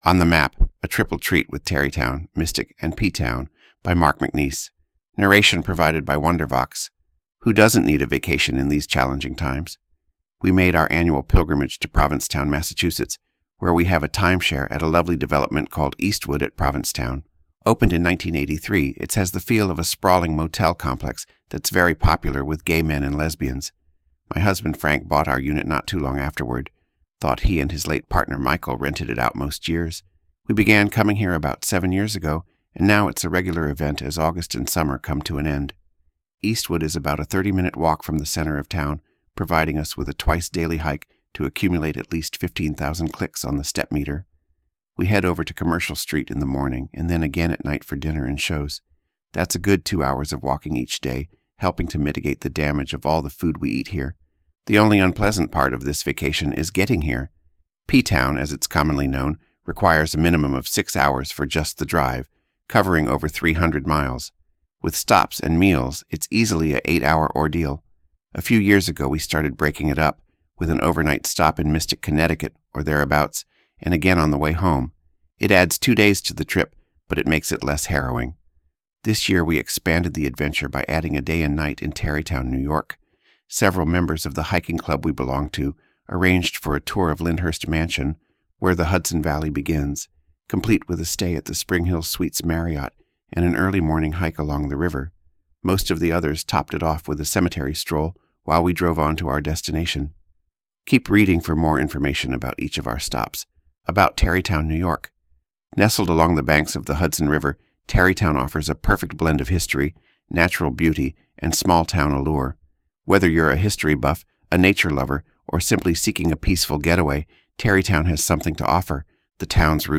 Narration provided by Wondervox